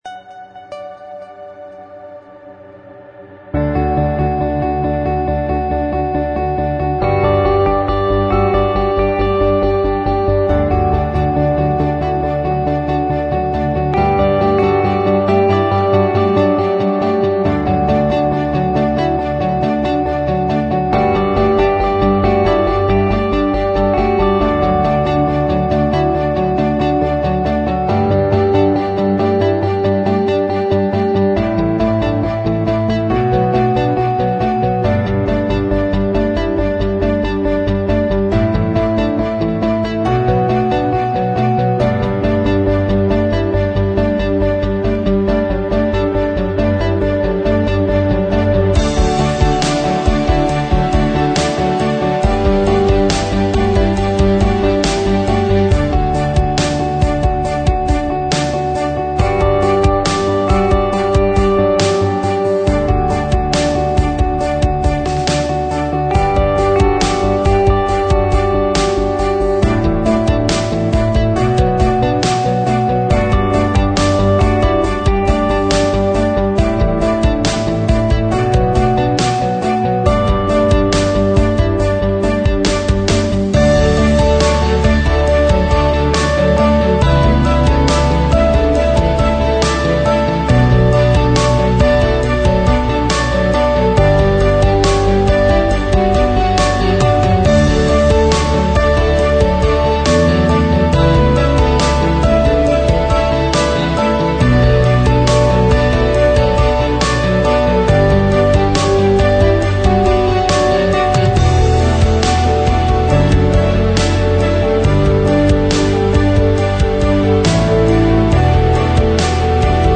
Sample Rate 采样率16-Bit Stereo 16位立体声, 44.1 kHz